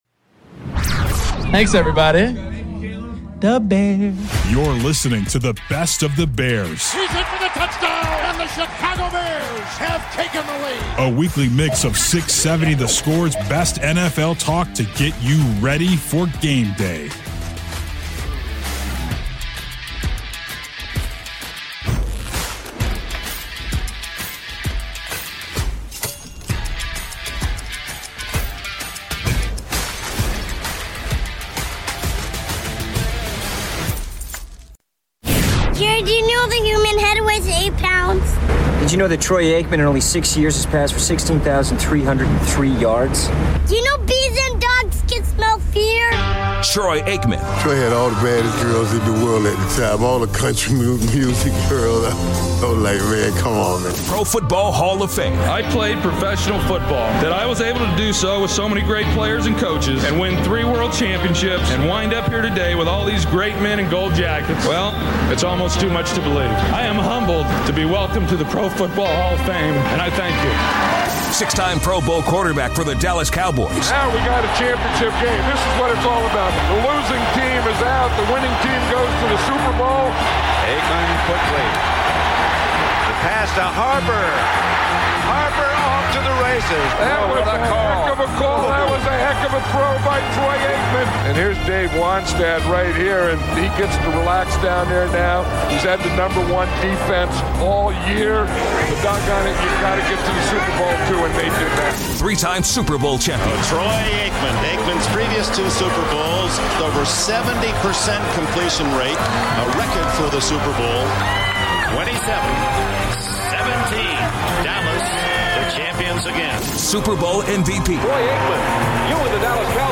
smart and compelling Chicago sports talk with great listener interaction.